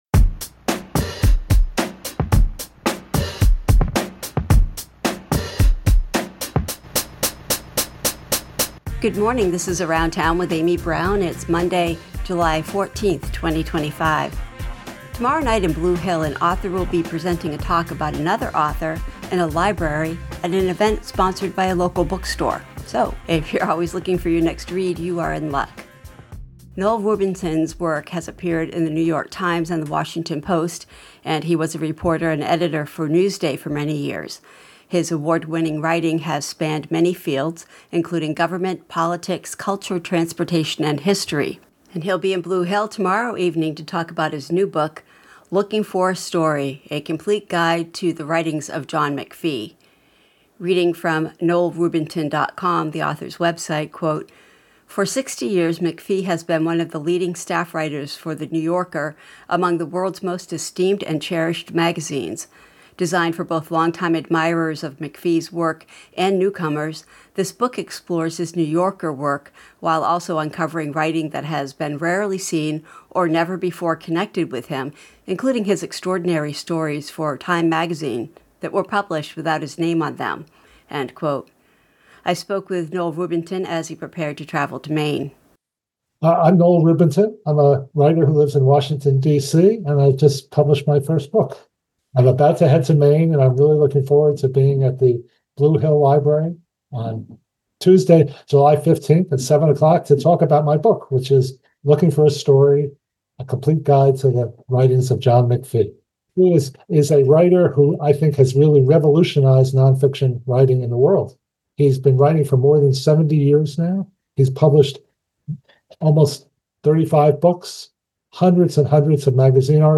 We spoke with him as he was preparing to travel to Maine